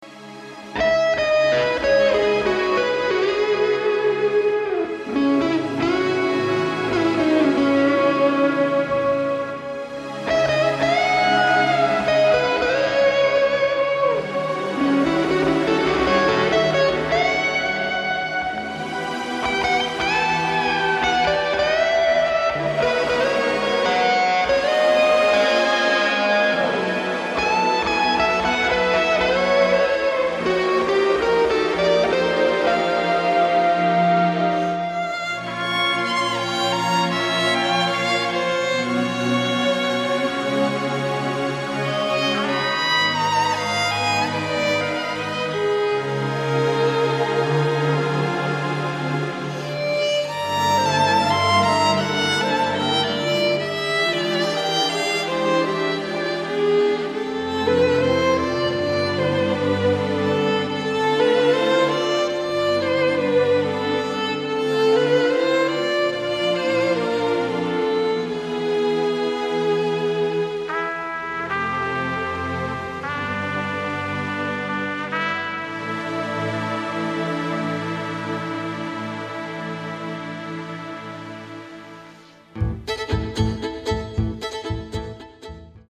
modern choreography and conceptual music.